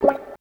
137 GTR 12-R.wav